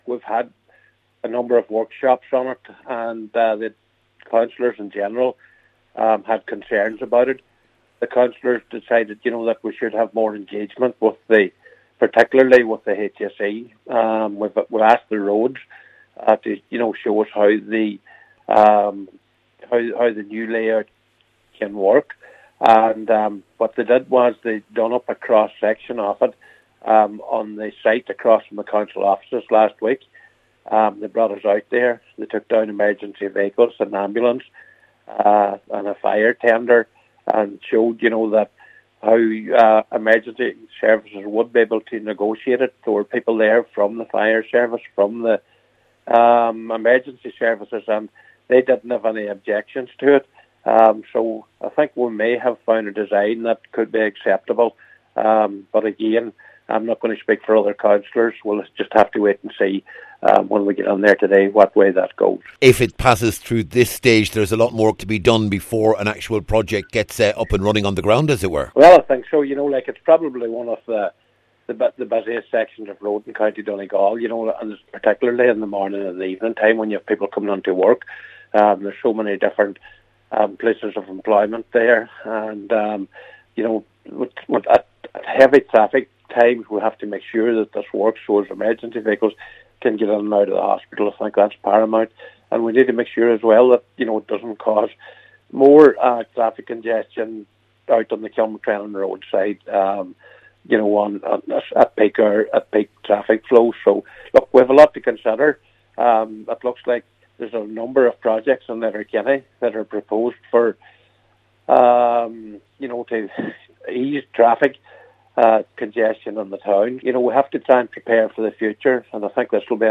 Cllr Michael McBride says he believes the proposal is viable….…….